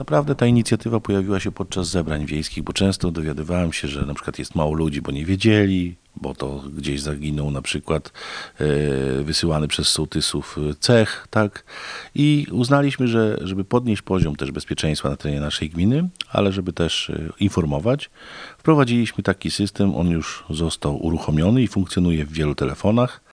– Jest to usługa czysto praktyczna i powstała z praktycznych względów – tłumaczy Tomasz Osewski – wójt gminy Ełk.